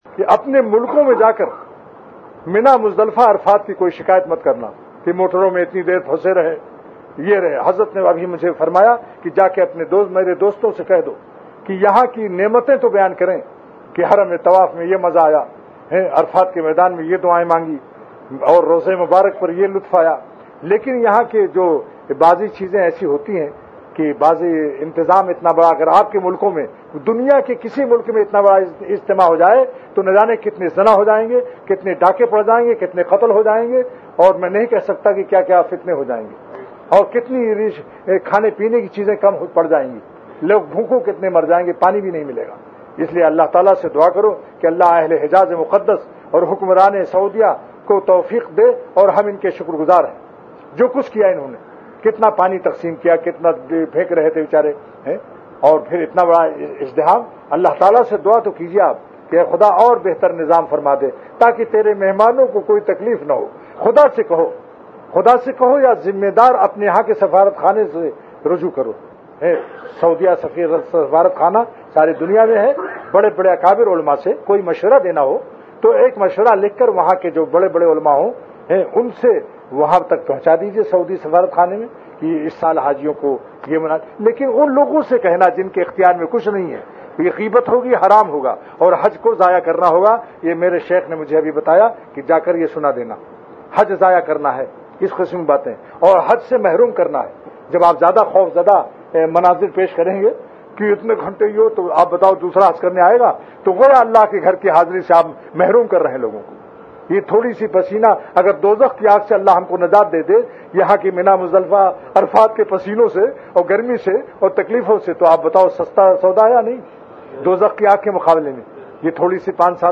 by Hazrat Maulana Shah Hakeem Muhammad Akhtar Sahab R.A
CategoryHajj Bayanat
VenueMakkah Mukarrama
Event / TimeAfter Zuhur Prayer